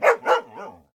bdog_panic_2.ogg